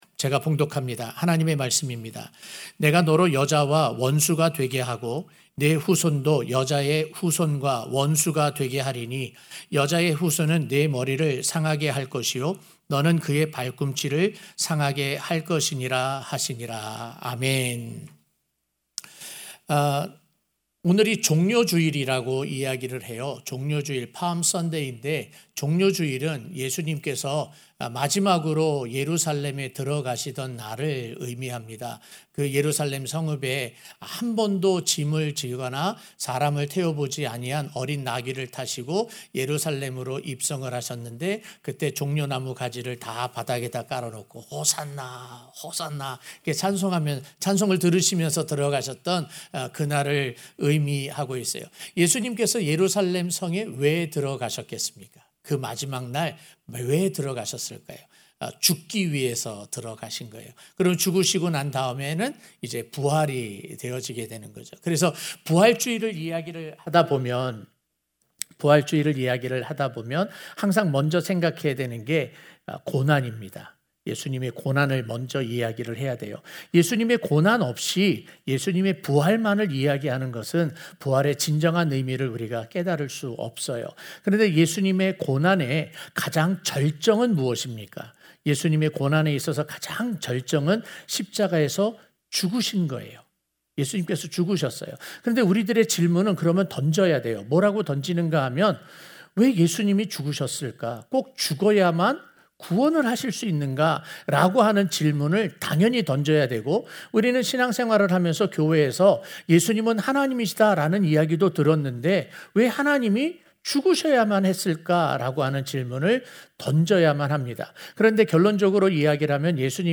2021년 3월 28일 주일오전예배 설교입니다. 12월 5일 설교와 본문이 같아서 비교해 보고자 다시 올렸습니다.